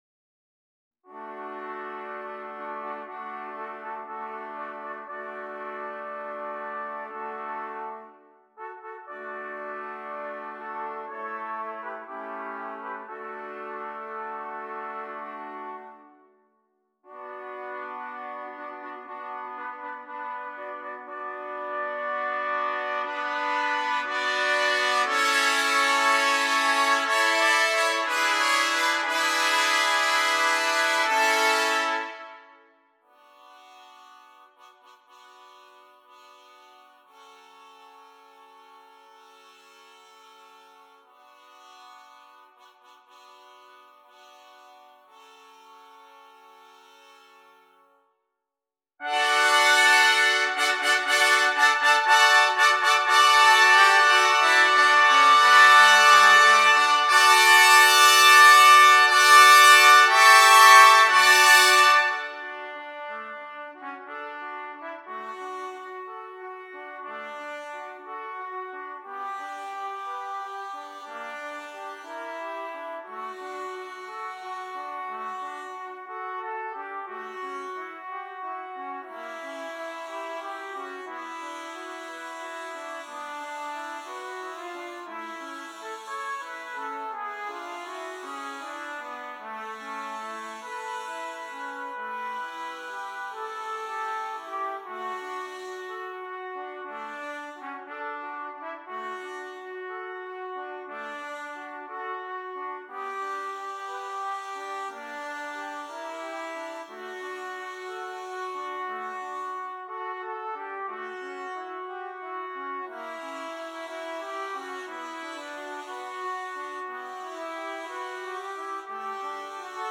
8 Trumpets